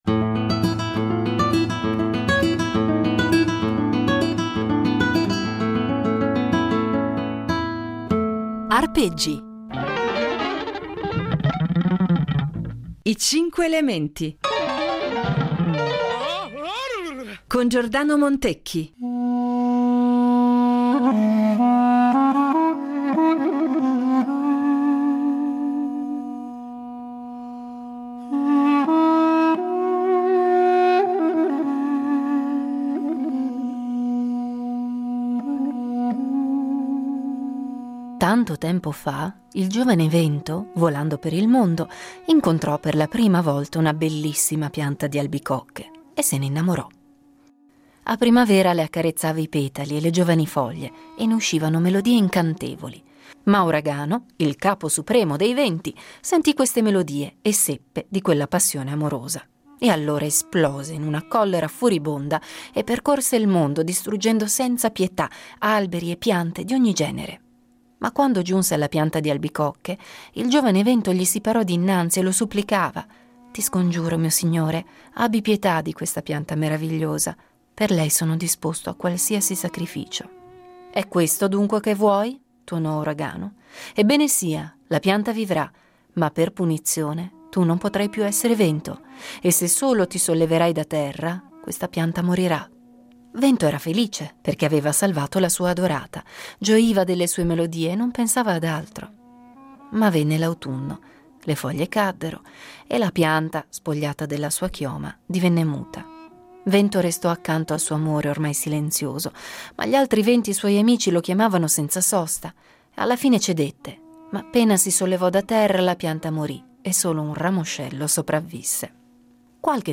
Duduk (5./10)